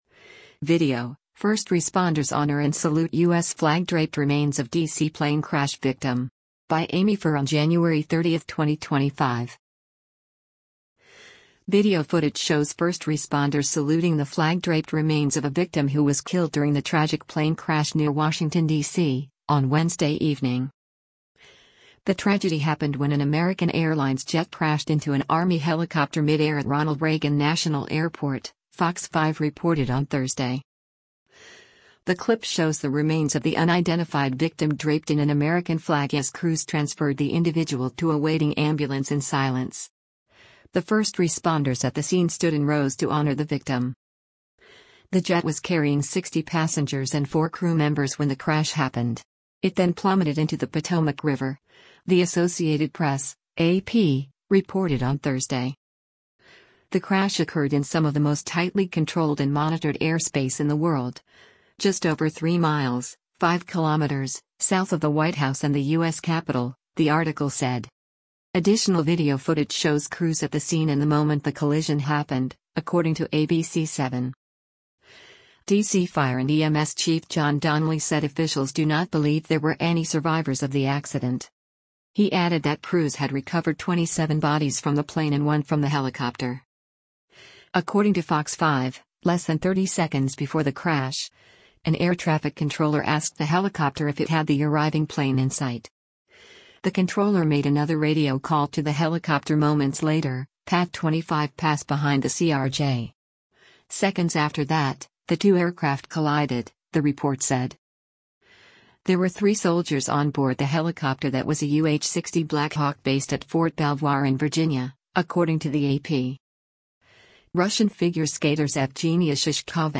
The clip shows the remains of the unidentified victim draped in an American flag as crews transferred the individual to a waiting ambulance in silence. The first responders at the scene stood in rows to honor the victim.